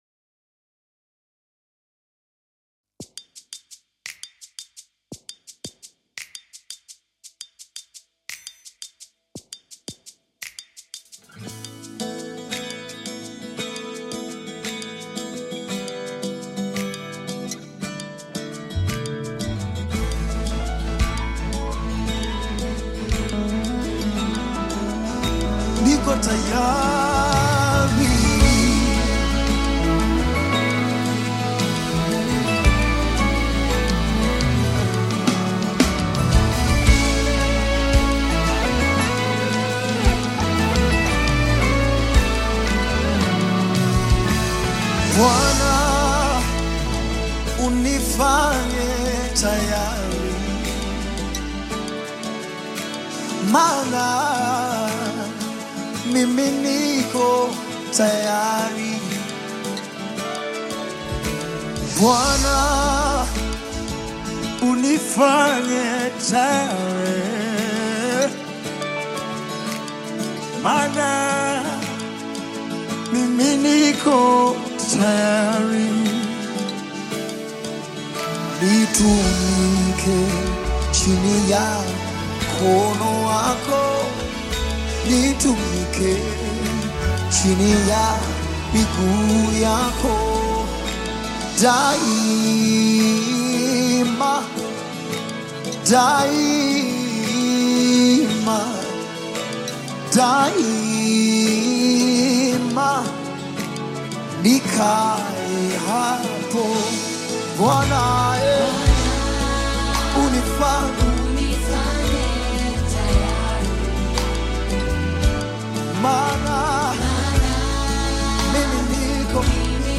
Gospel song